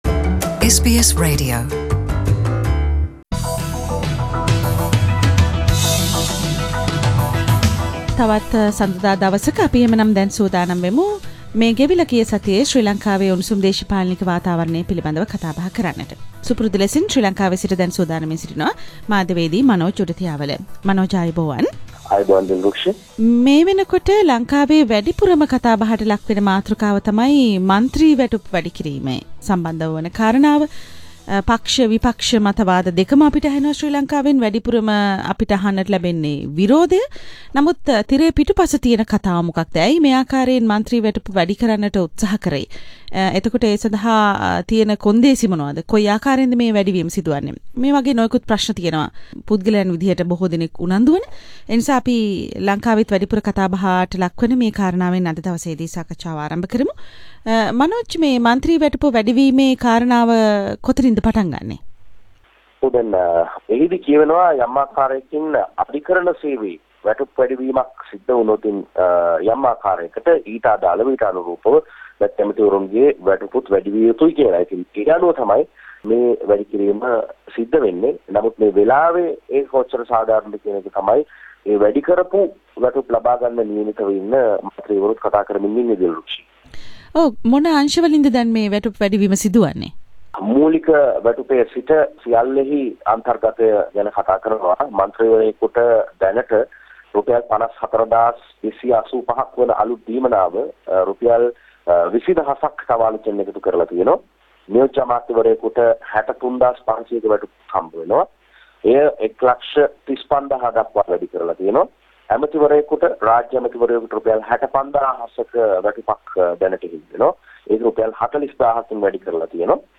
පුවත් සමාලෝචනය